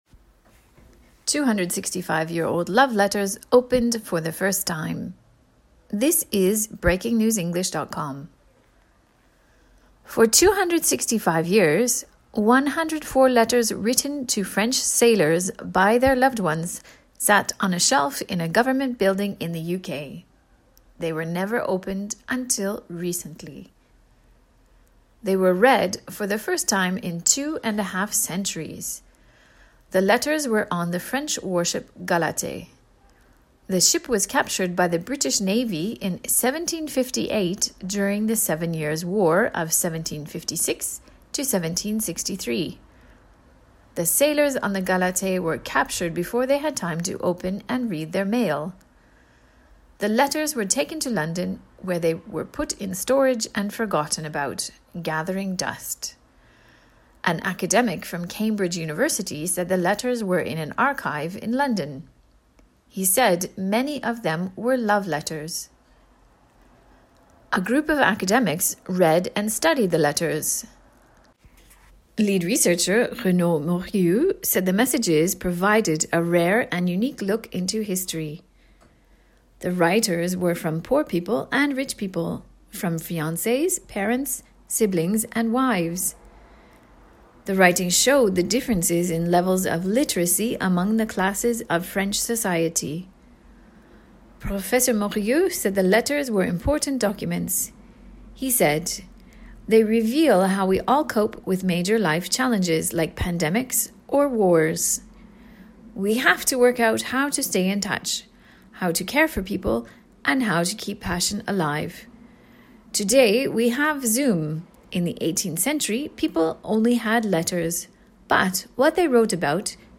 AUDIO(Normal)